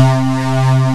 FUNK C4.wav